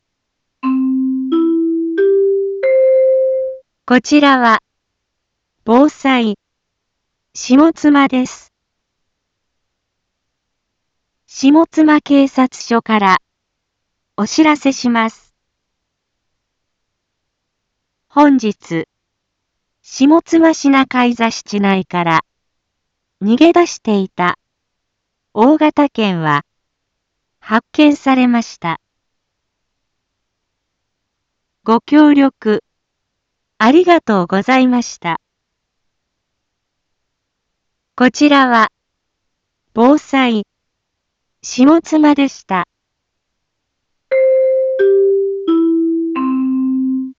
一般放送情報
BO-SAI navi Back Home 一般放送情報 音声放送 再生 一般放送情報 登録日時：2025-03-26 13:31:07 タイトル：大型犬の発見について インフォメーション：こちらは、ぼうさい、しもつまです。